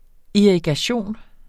Udtale [ iɐ̯igaˈɕoˀn ]